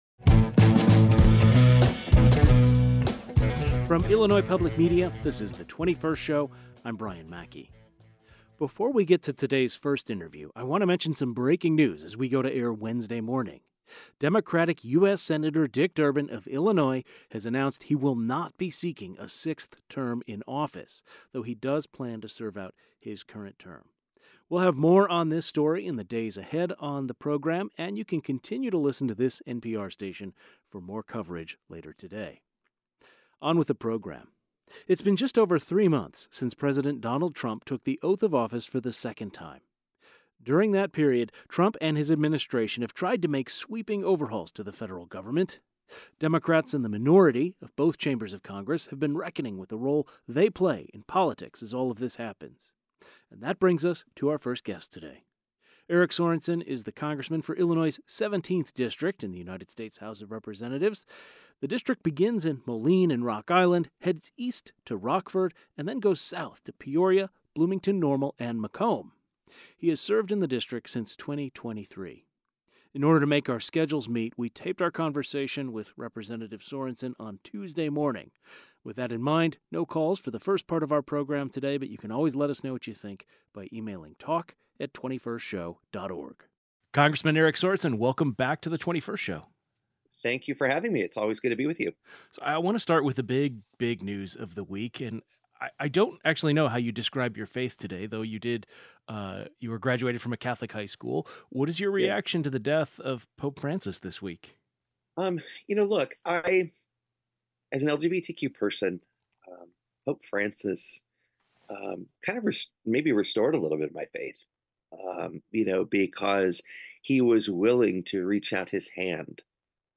GUEST Congressman Eric Sorensen Democrat, IL-17 Tags